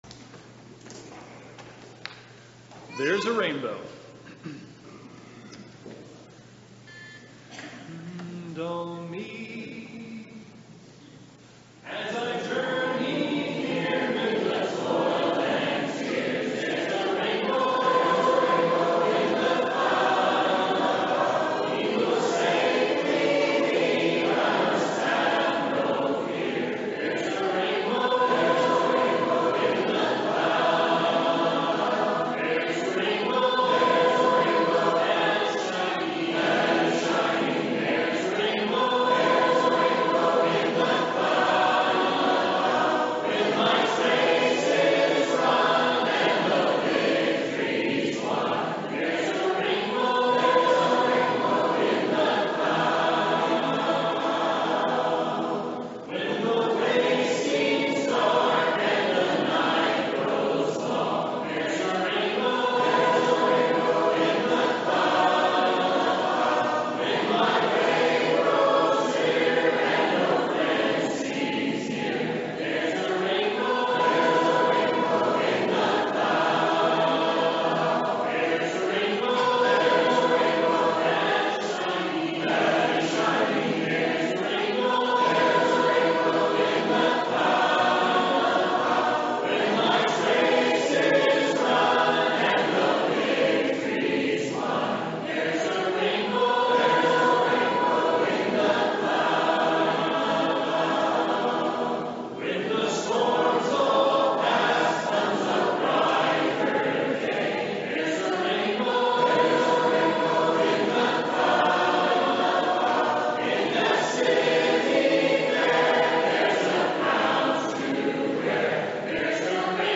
July End of Month Singing